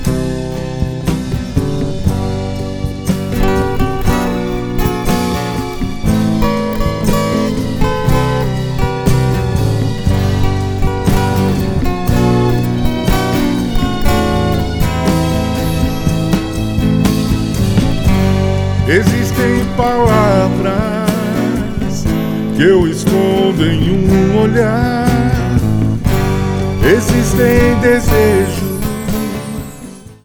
Baixo/Percussão/Violão/Teclado